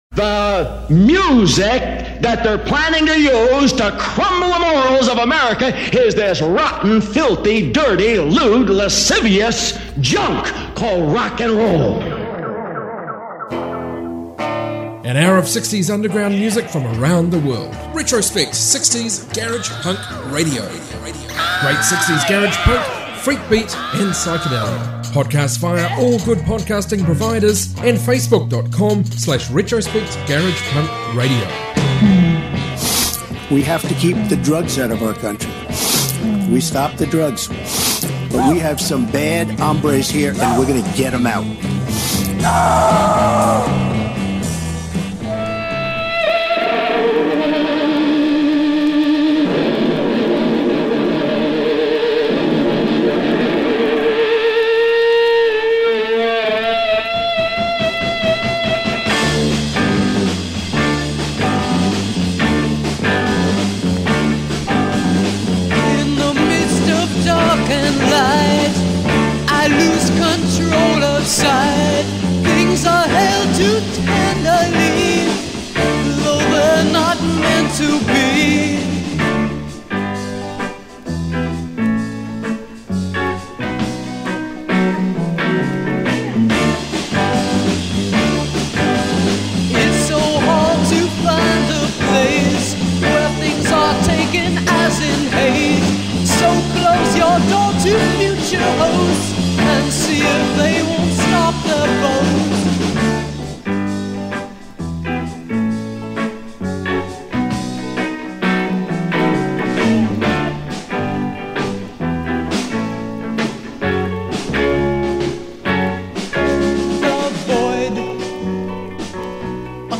60s garage rock